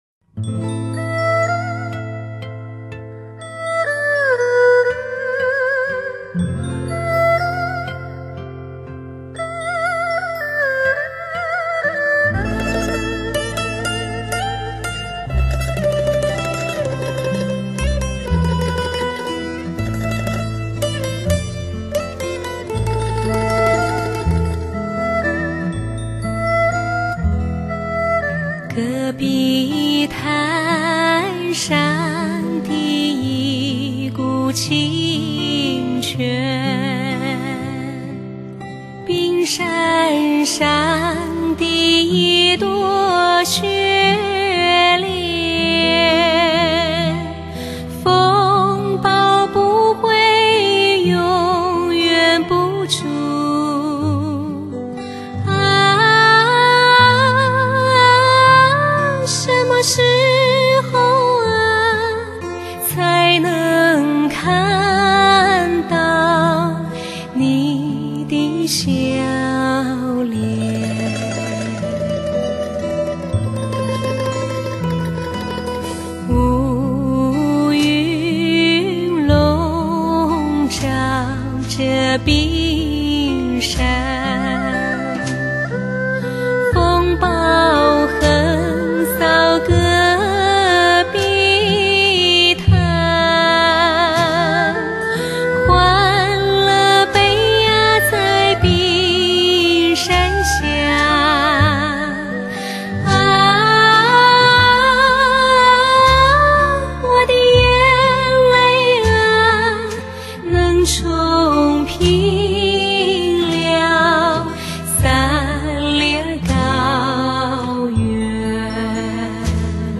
流行唱法与民族唱法相结合 音色光滑圆润 自成妙韵
精英联手 共创非凡 磁性女声 清纯甜美
声音平衡 定位清晰 给你极品享受